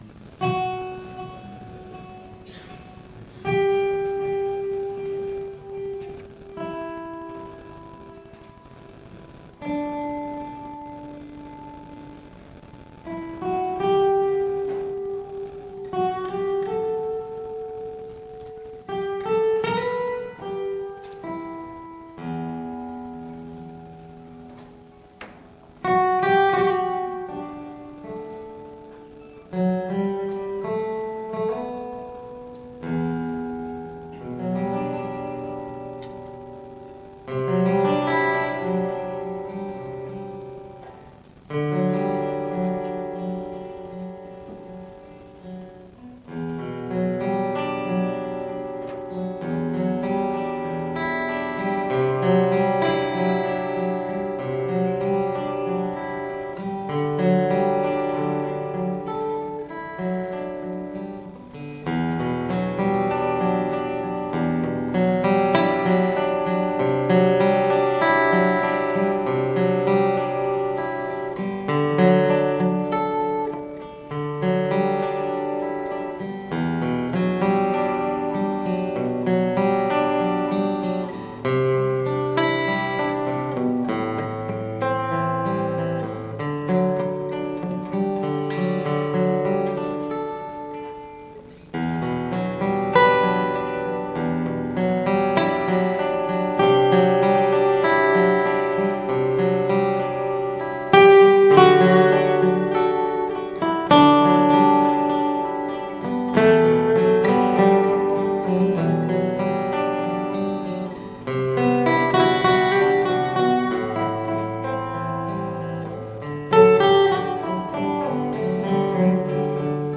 Audiodokumentation Bundeskongress 2017: „Erinnern und Zeichen setzen! – Zeugnisse politischer Verfolgung und ihre Botschaft.“ 28./29./30. April 2017, Magdeburg, Maritim Hotel (Teil 1: 28. April)
Gitarrenimprovisation